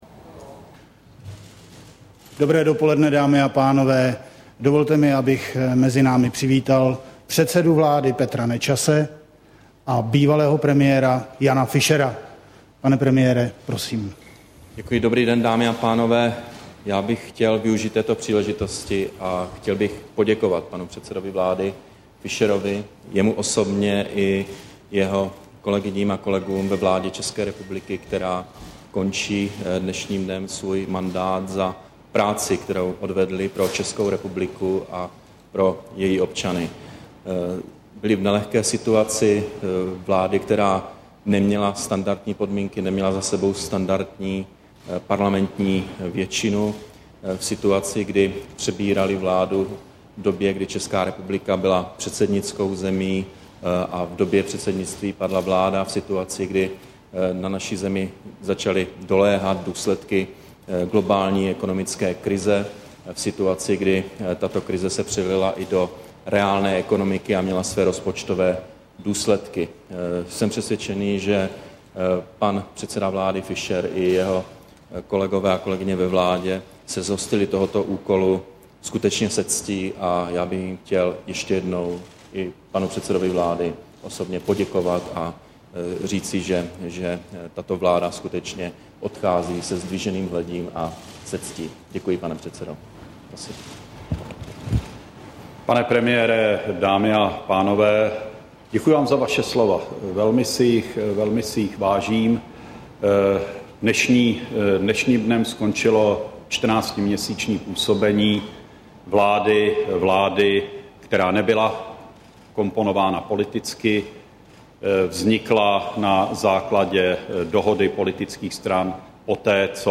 Prohlášení Jana Fischera a Petra Nečase po přivítání ve Strakově akademii
brifink-premieru-13-7-10.mp3